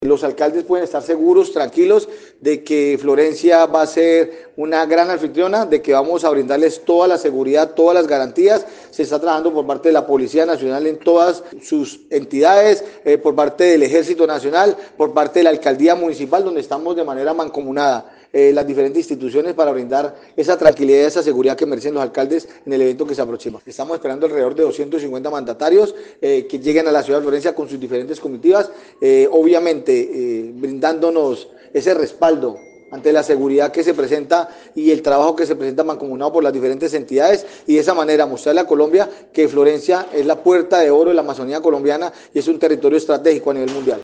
El secretario de gobierno municipal, Carlos Humberto Mora Trujillo, anunció que, la cumbre, se llevará a cabo del 11 al 13 de septiembre, contará con un operativo de seguridad robusto, con refuerzos adicionales de la Policía y el Ejército, así como un plan de contingencia ante la posible llegada del presidente.